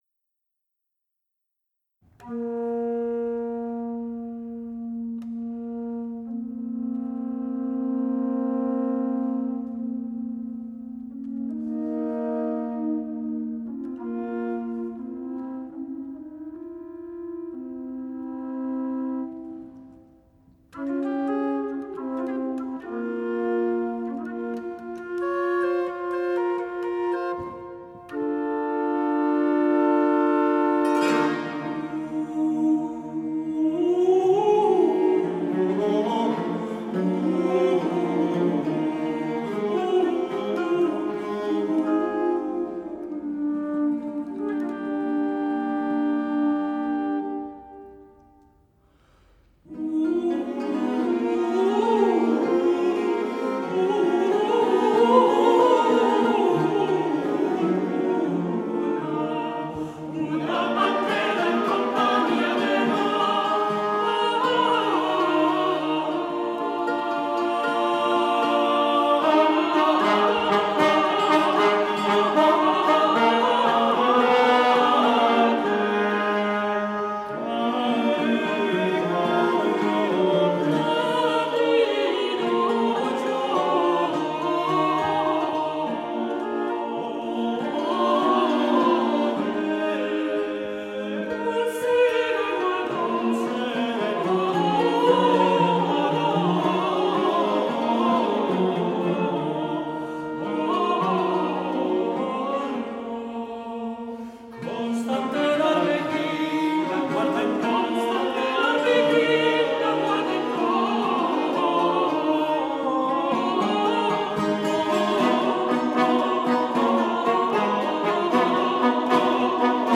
met tot twintig zangers en instrumentalisten